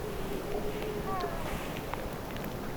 tuollainen lokin ääni, 2
tuollainen_lokin_aani_2.mp3